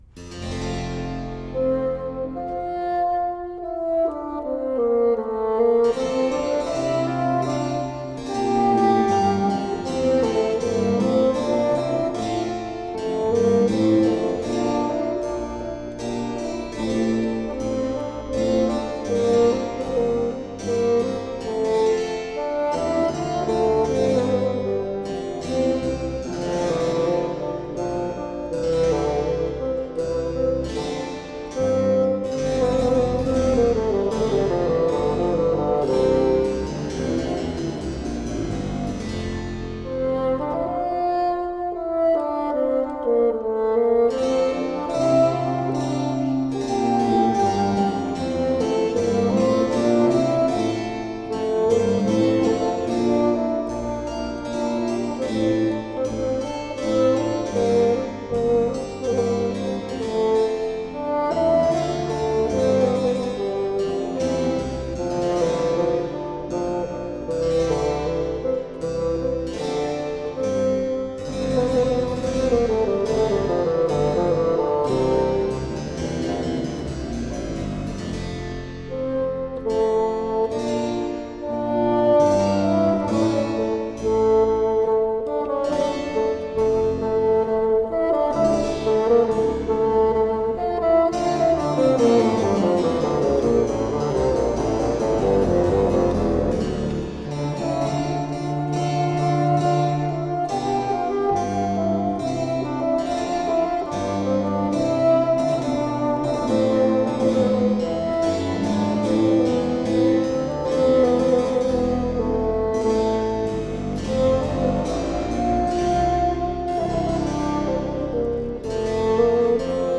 Buoyant baroque bassoon.
bright, warm tones